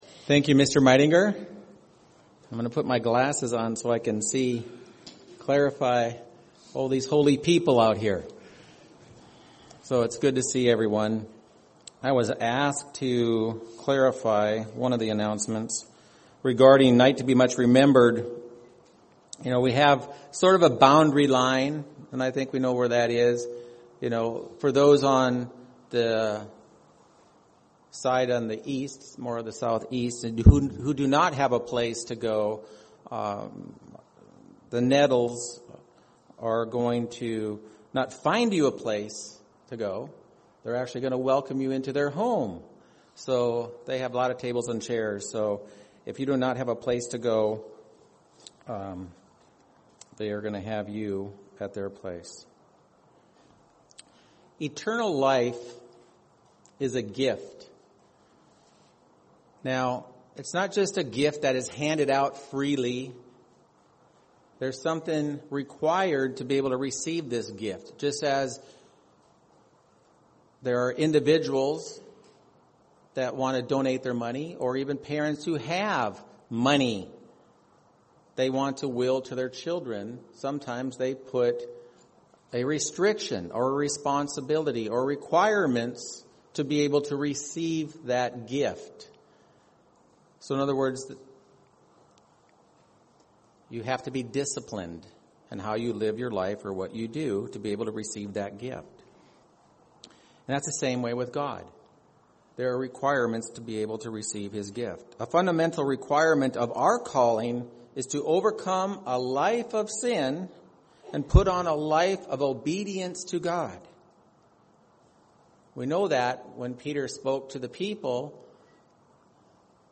Sermons
Given in Phoenix East, AZ